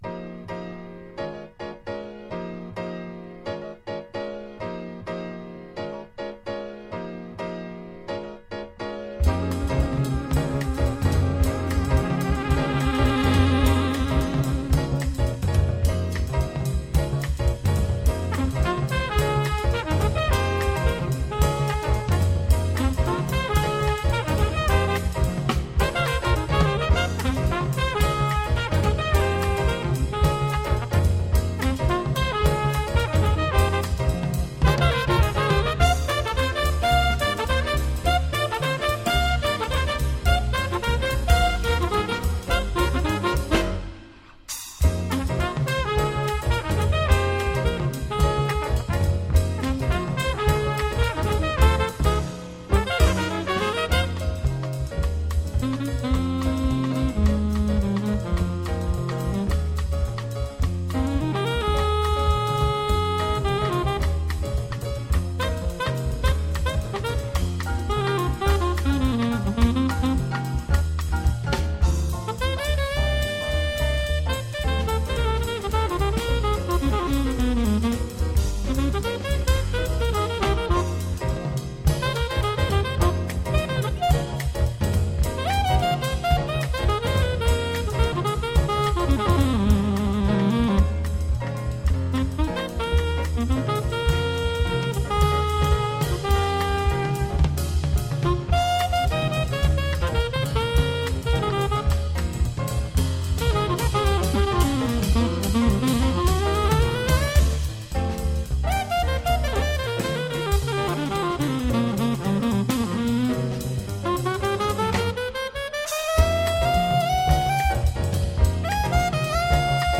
Marvelous modal jazz from this obscure French band.
LP